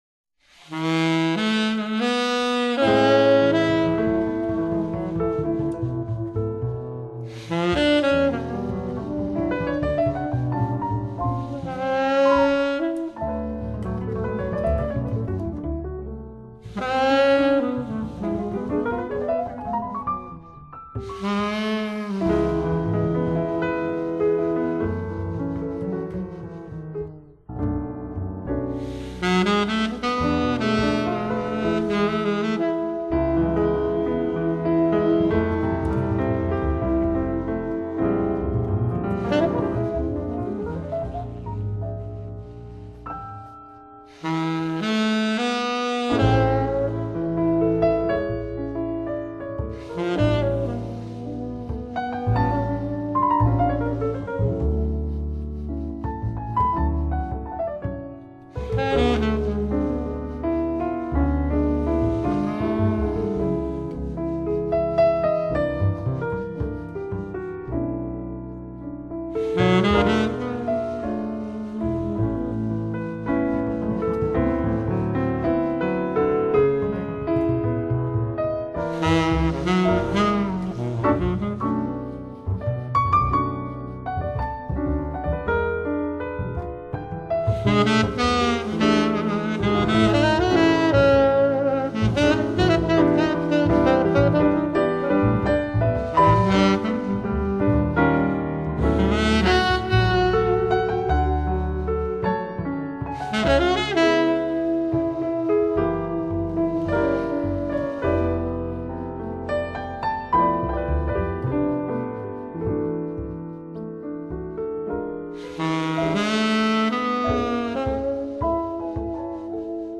萨克斯风与钢琴、贝斯，三者的关系可以如何巧妙处理，在这张专辑中获得一些解释。
专辑中所有演奏的乐曲皆有共同特质，不油腻也没有华丽装饰。